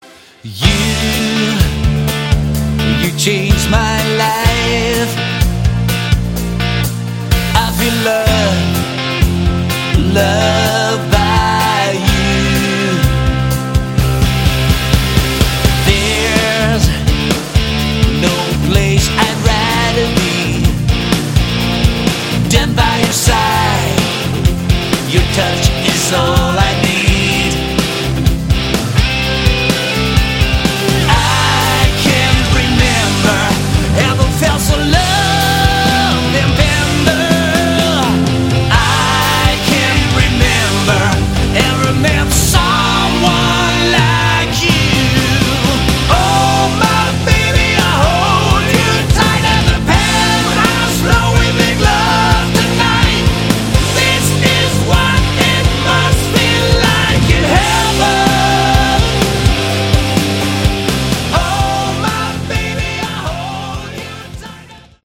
Category: Hard Rock
bass
lead guitar
rhythm guitar
keyboards
drums
lead vocals